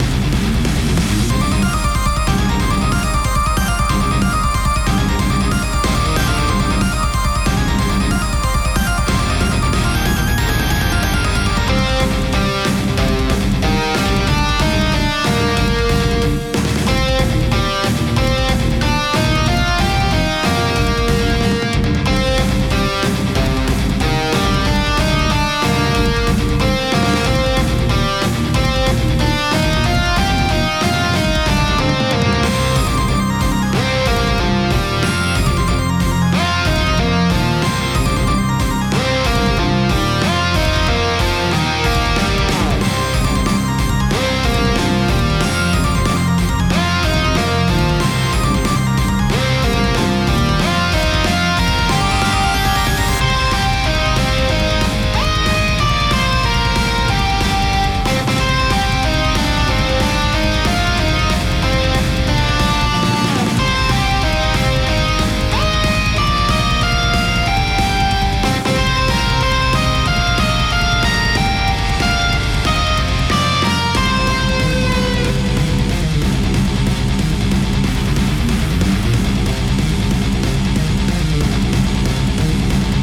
Music for battle theme.